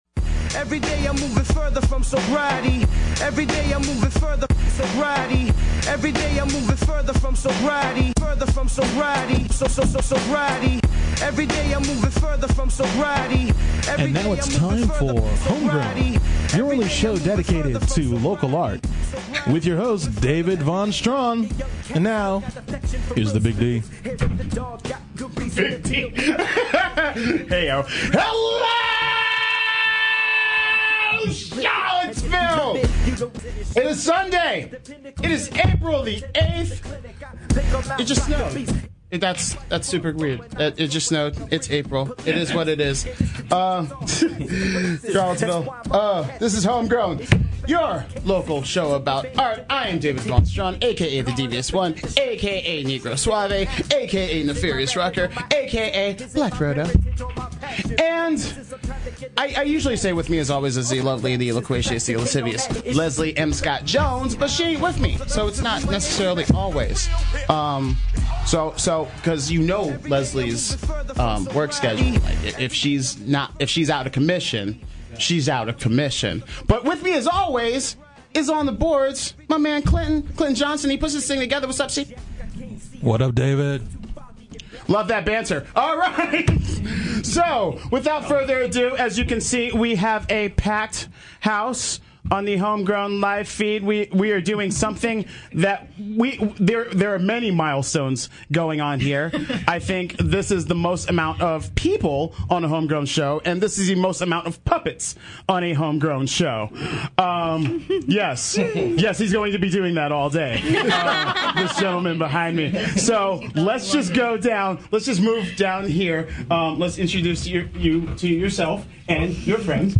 a unique Home Grown that welcomes two sets of guests together for the entire hour